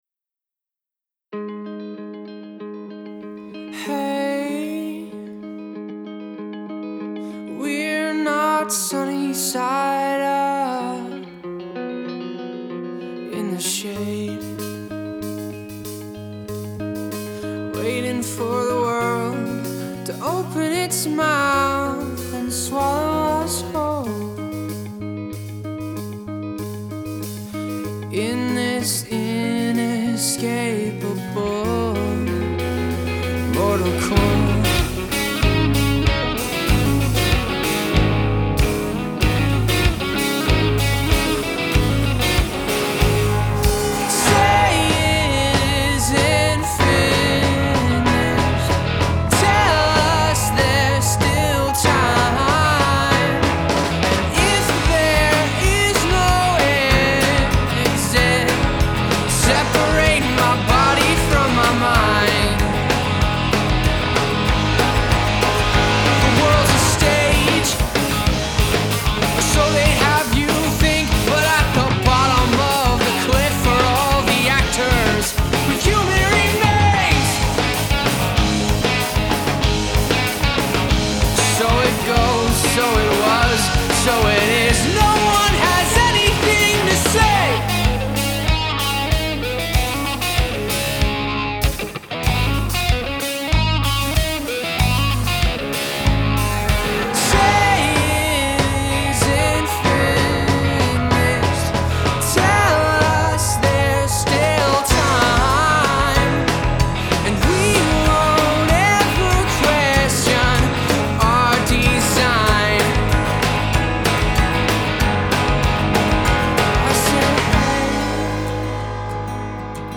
Rock song - production and mix advice much appreciated...or just listen and enjoy!
We tracked acoustic and vocals first, and then added and subtracted elements as we went. After tracking everything I started a new mix from scratch (helped immensely!) Drums and bass were played by a couple friends of mine as favors.
I think something just needs to be changed in the first pre-chorus.